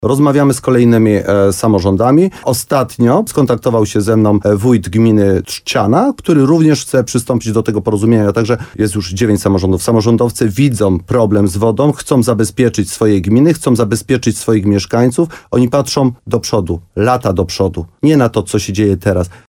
Jak powiedział Mirosław Cichorz, wójt gminy Mszana Dolna, na horyzoncie pojawiają się kolejne chętne gminy, na przykład z powiatu bocheńskiego.